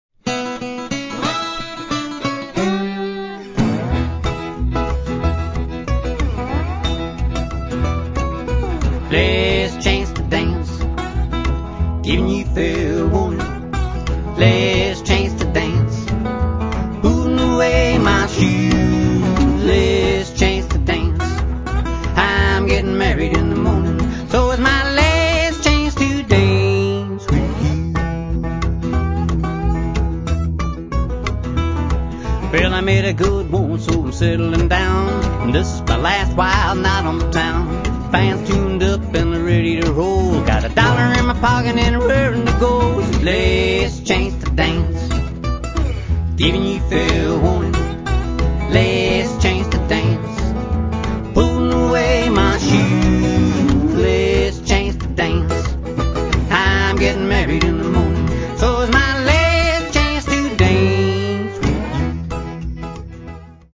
guitar
a definite old-time country feel to it